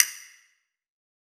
6CASTANET.wav